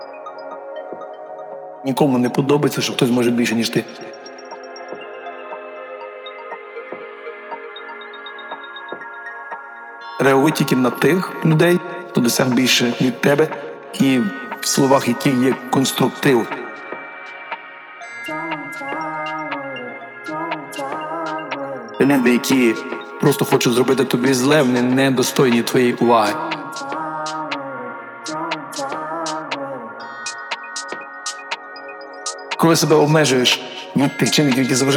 Жанр: Танцевальные / Украинские